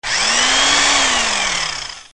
Sega elettrica a nastro
Suono corto di sega elettrica.